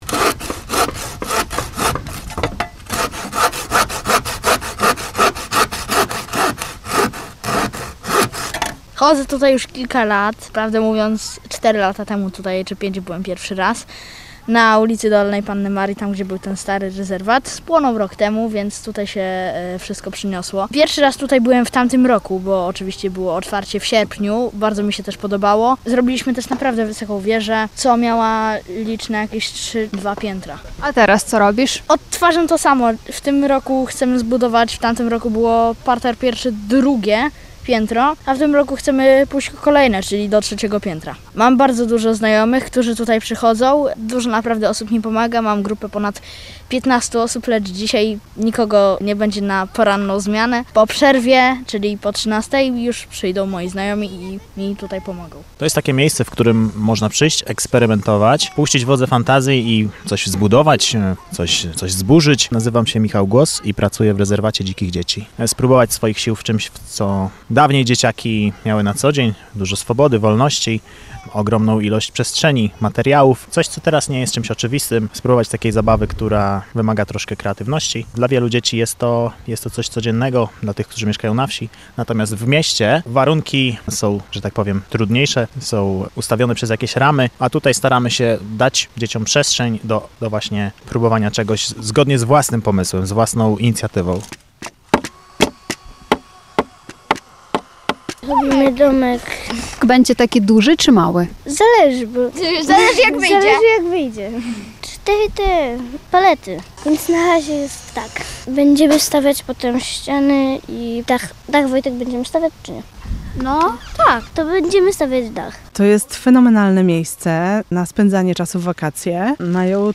Nasza dziennikarka obserwowała, czym zajmują się dzieci podczas pobytu na terenie rezerwatu dzikich dzieci.